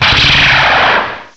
cry_not_zoroark.aif